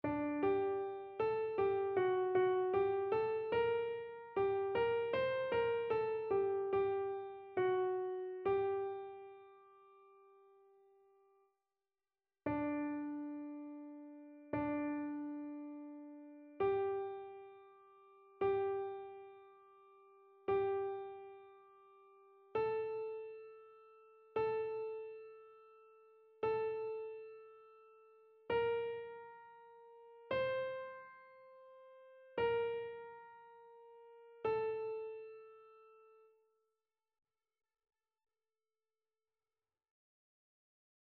Chœur
Soprano